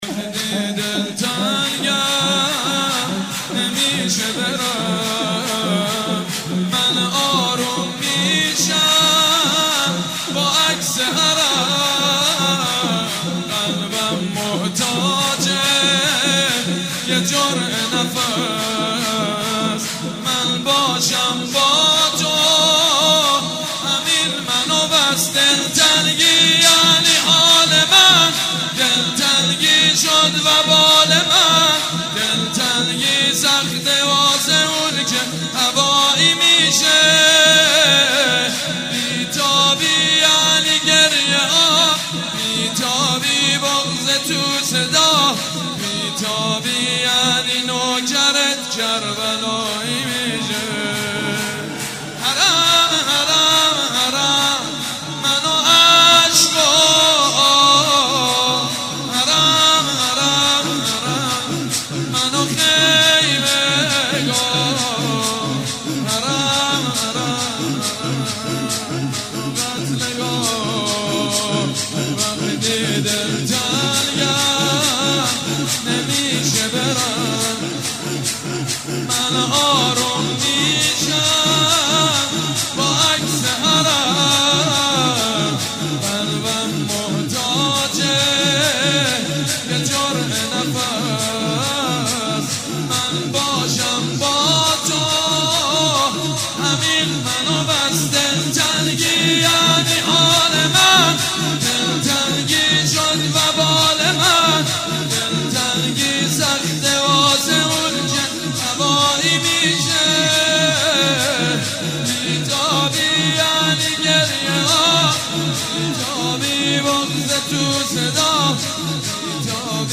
جشن میلاد حضرت علی اکبر(ع) در هیئت ریحانة الحسین(س)
حاج سیدمجید بنی فاطمه/شور/دلتنگی یعنی